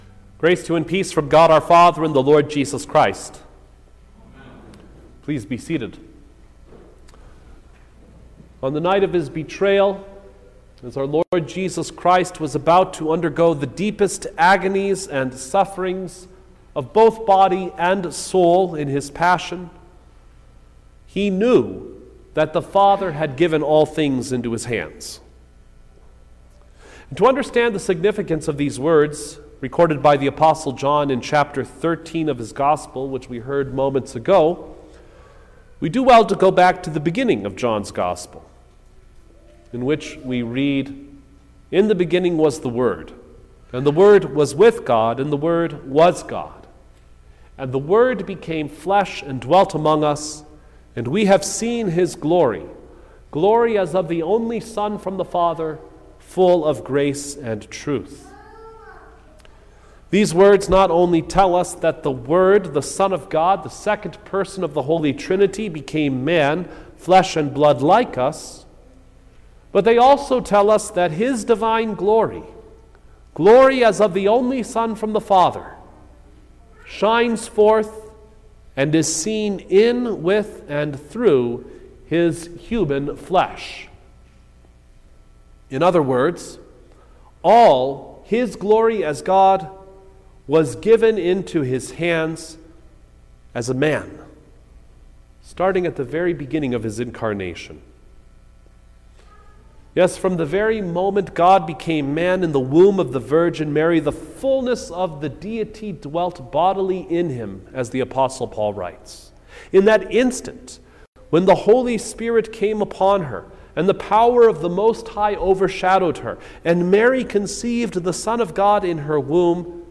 April-6_2023_Maundy-Thursday_Sermon-Stereo.mp3